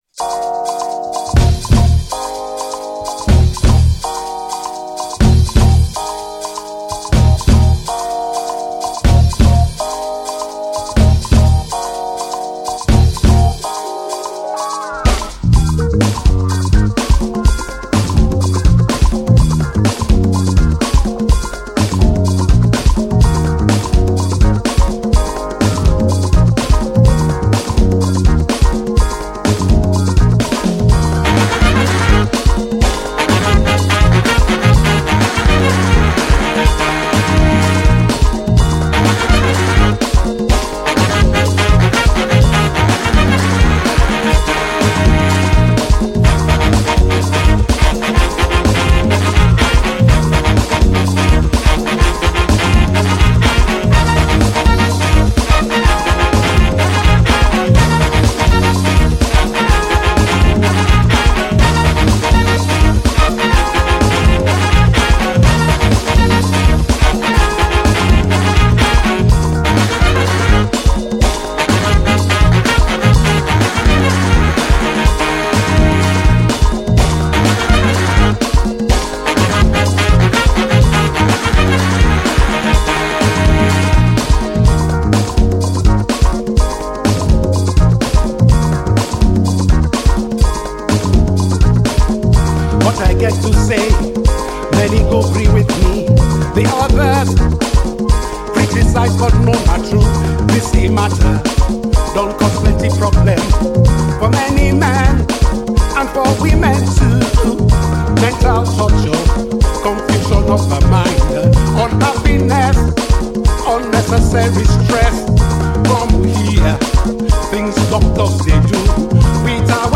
the legendary Afrobeat musician